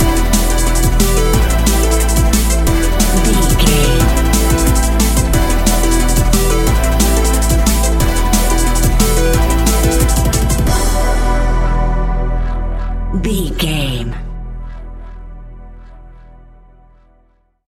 Epic / Action
Fast paced
Aeolian/Minor
aggressive
dark
driving
energetic
intense
futuristic
synthesiser
drum machine
electronic
sub bass
synth leads
synth bass